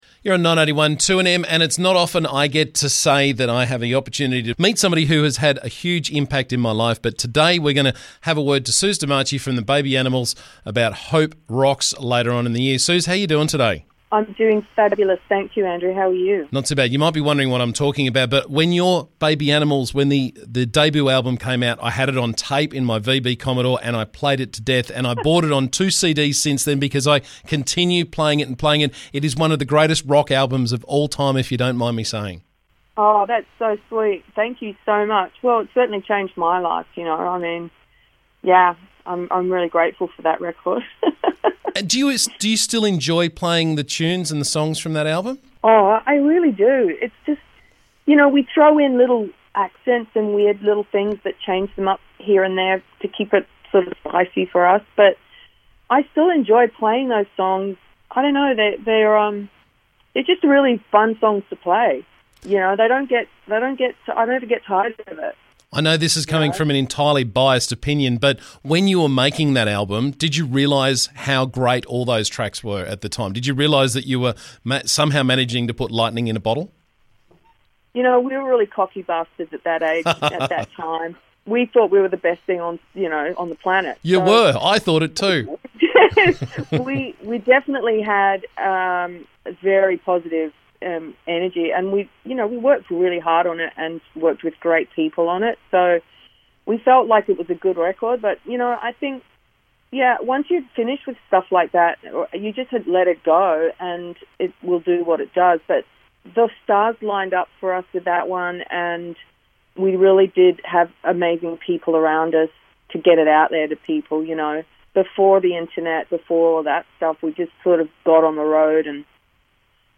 Hope Rocks at Hope Estate this October and Suze DeMarchi will be there with the Baby Animals. She joined me this morning to talk about the event and so I could lavish her with praise for one of the greatest rock albums of all time.